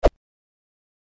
جلوه های صوتی
دانلود صدای باد 79 از ساعد نیوز با لینک مستقیم و کیفیت بالا